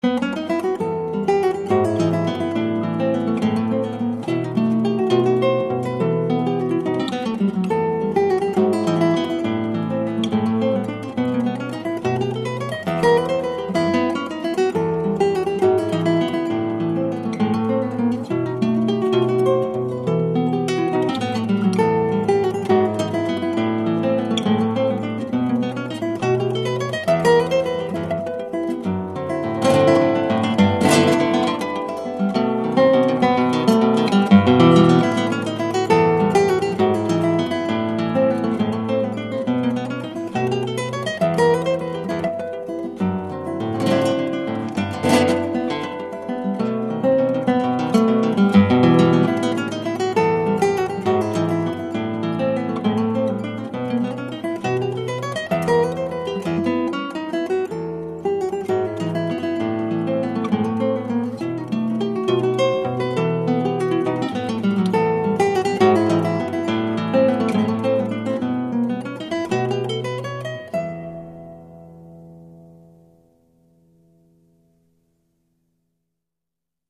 0169-吉他名曲华尔兹.mp3